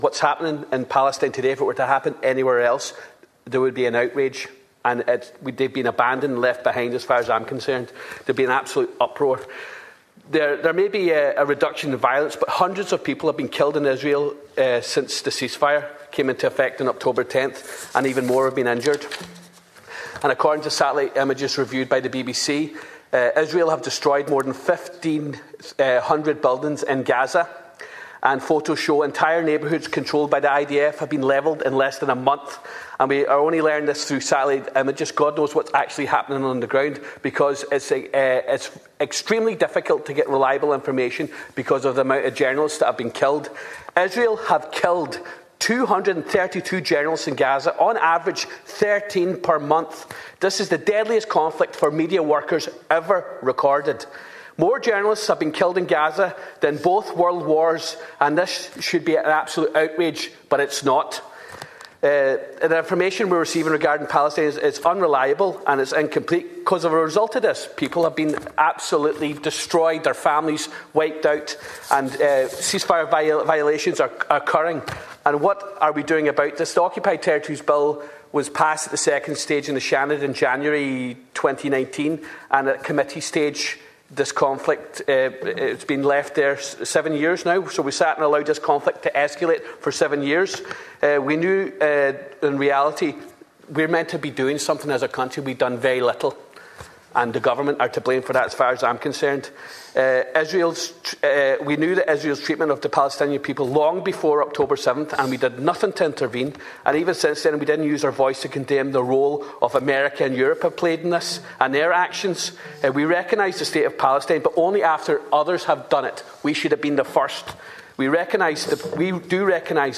Donegal Deputy Charles Ward was one of those who co-signed the motion.
He told the Dail the reality is that what is being described as a ceasefire in Gaza is anything but…………